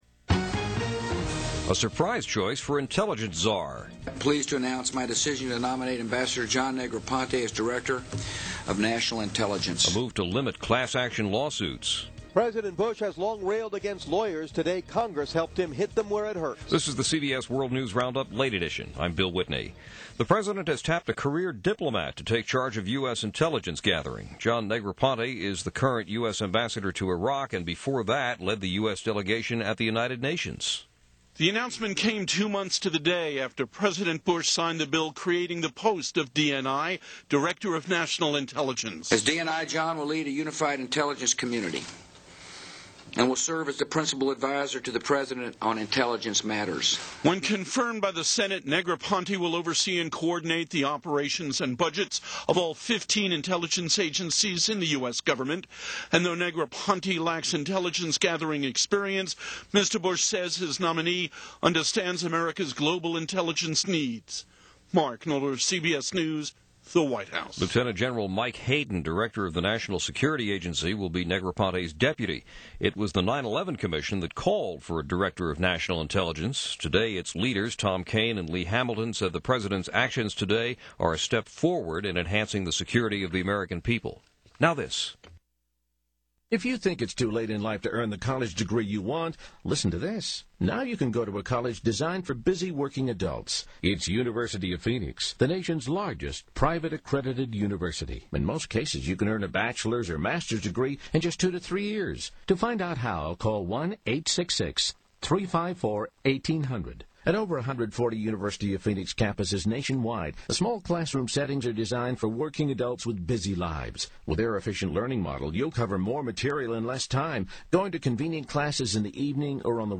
And that’s just a tiny slice of what went on, this February 17, 2005 as presented by The CBS World News Roundup Late Edition.